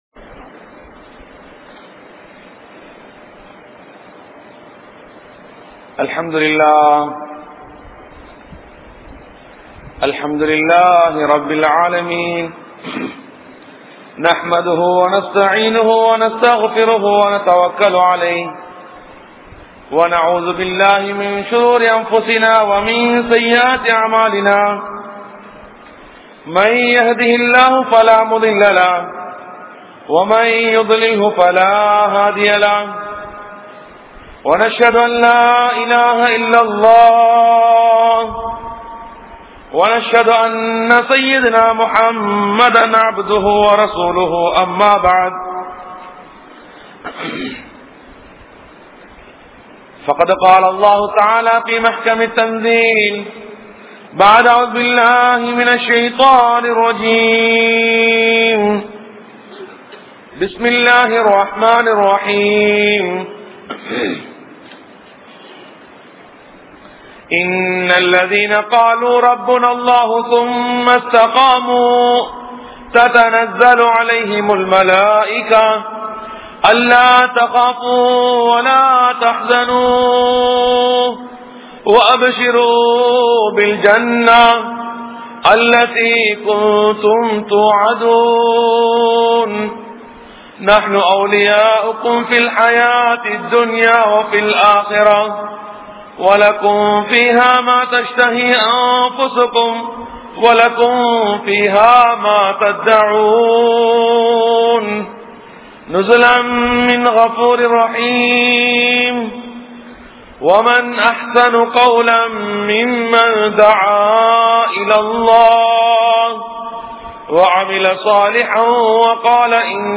Allah`vai Marantha Manitharhal (அல்லாஹ்வை மறந்த மனிதர்கள்) | Audio Bayans | All Ceylon Muslim Youth Community | Addalaichenai